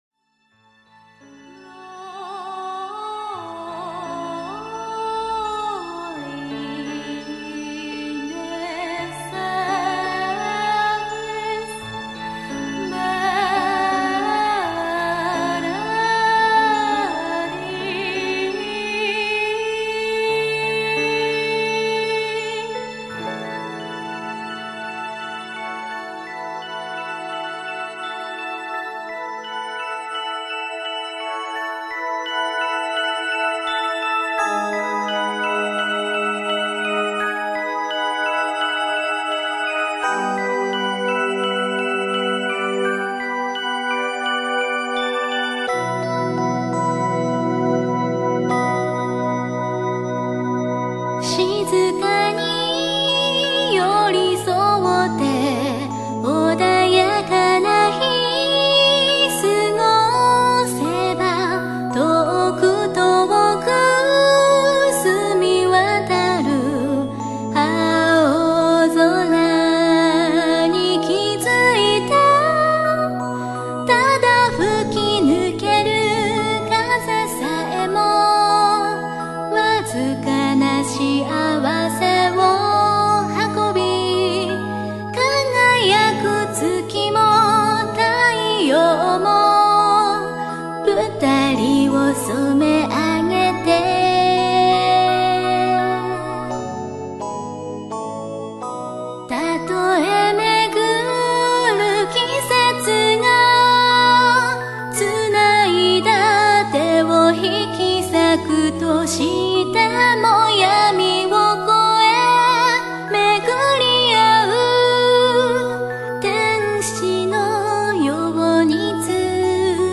エンディングテーマソング